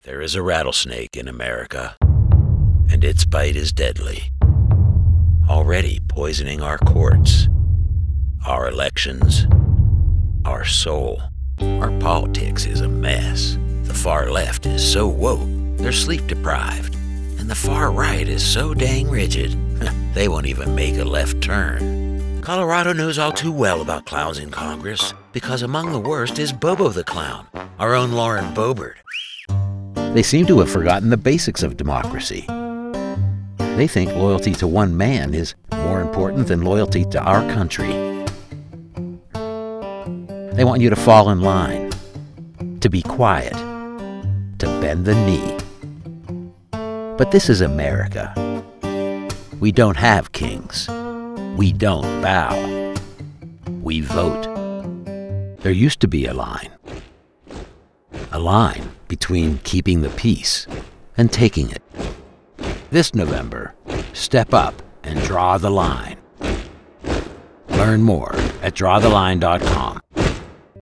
Male Democratic Voices
Voice actors with deep experience, pro home studios and Source Connect.